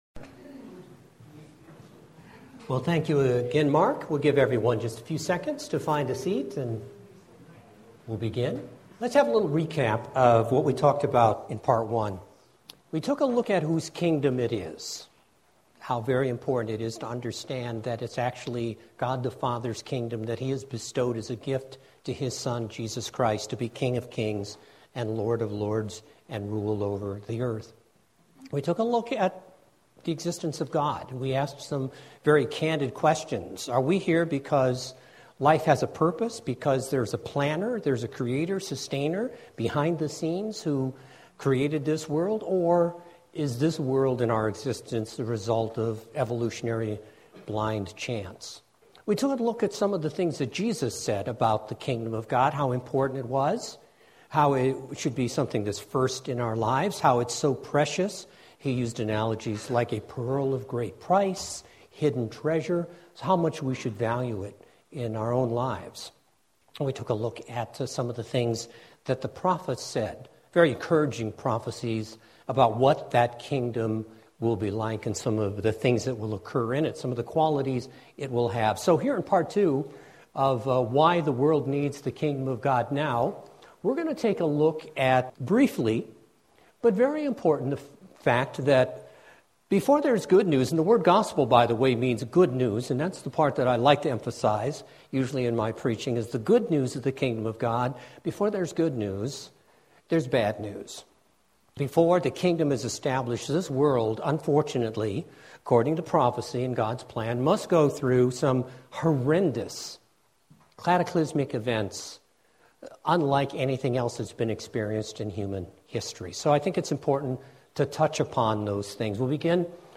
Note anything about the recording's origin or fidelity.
This was the 2nd part of a local Kingdom of God seminar given in the greater Cleveland area.